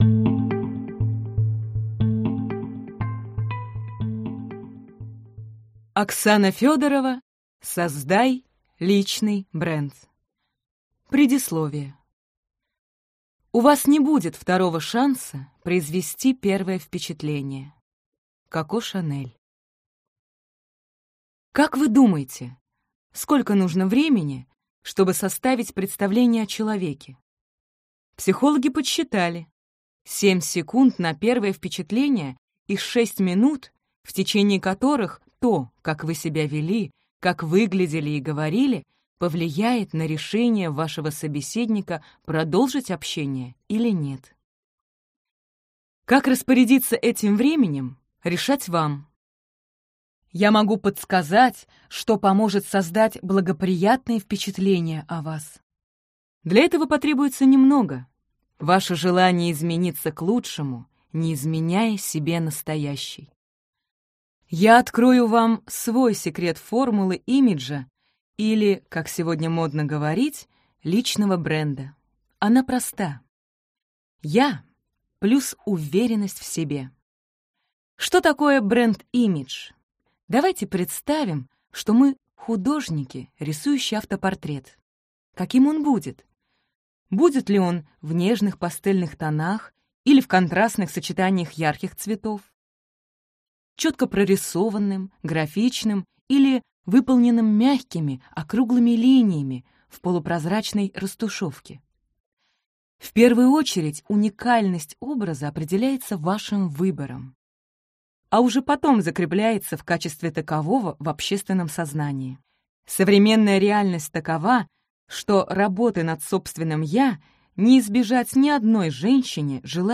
Аудиокнига Создай личный бренд | Библиотека аудиокниг